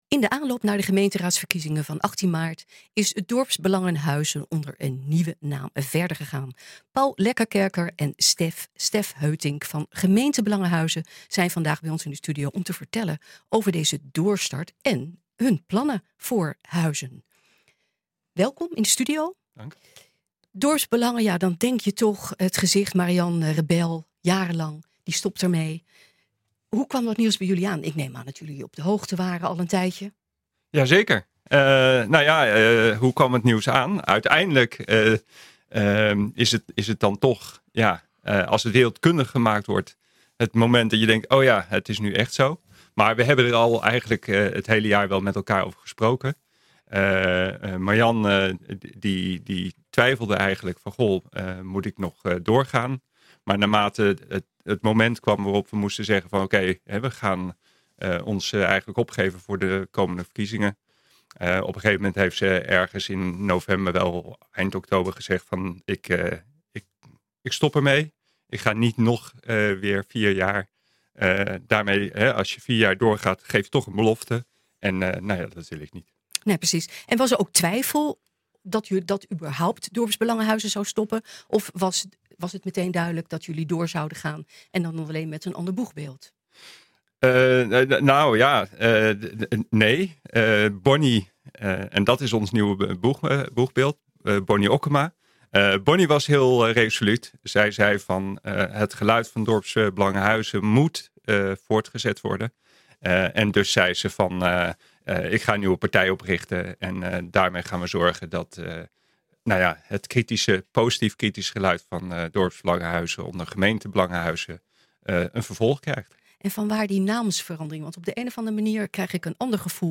zijn vandaag bij ons in de studio om te vertellen over deze doorstart en hun plannen voor Huizen.